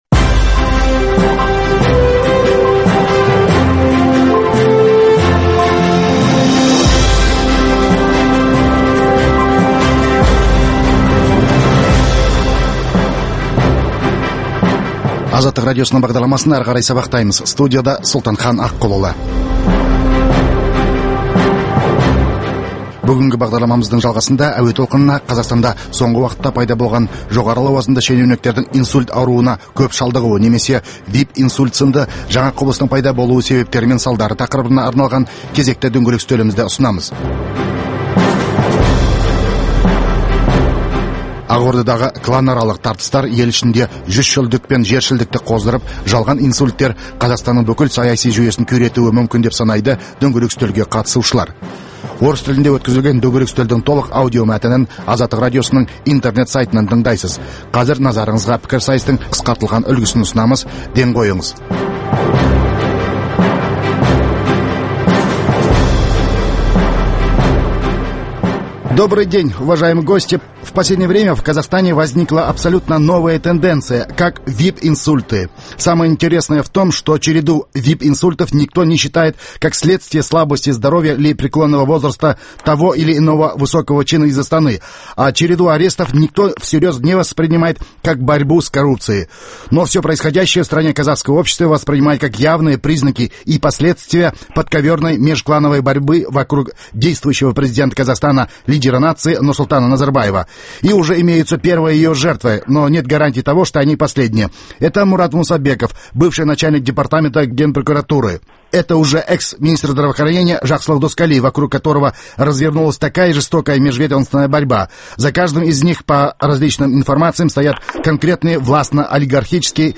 Дөңгелек үстел